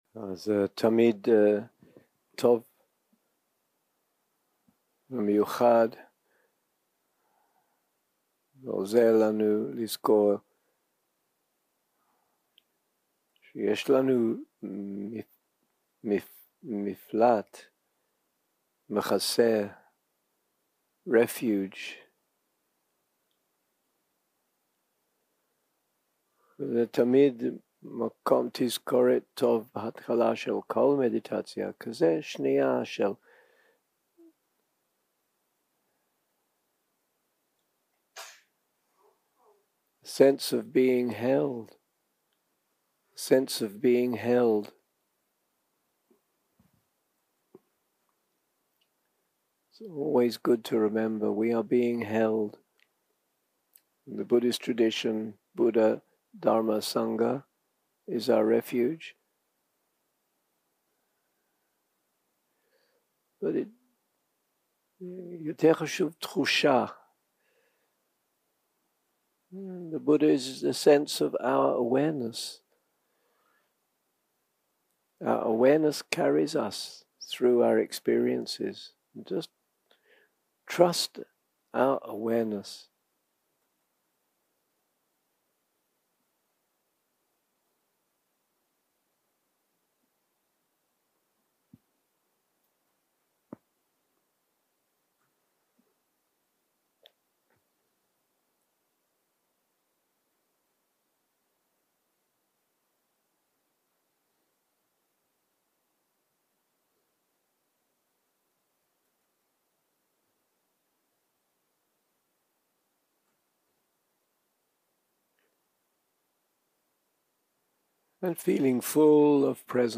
יום 5 - הקלטה 11 - צהרים - מדיטציה מונחית - נוכחות ומטא
Dharma type: Guided meditation שפת ההקלטה